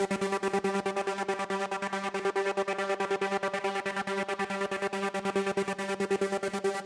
VTS1 Space Of Time Kit Melody & Synth